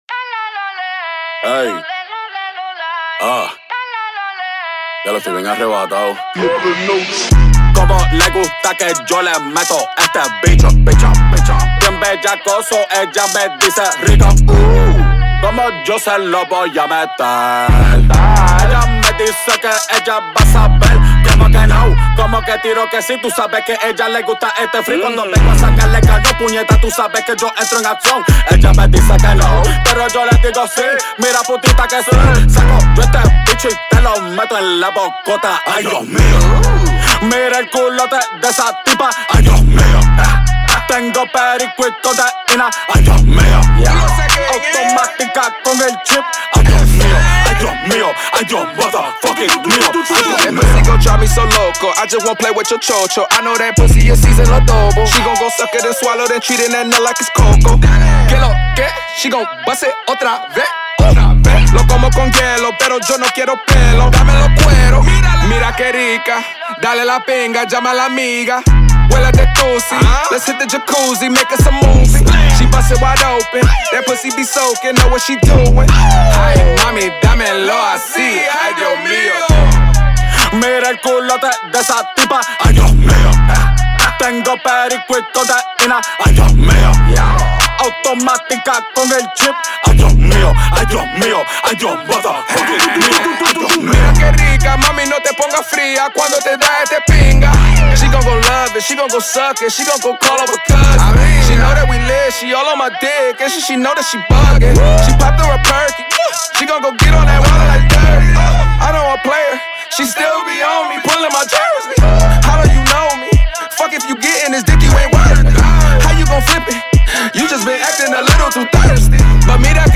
Hiphop
Hispanic Caribbean flavor to hip hop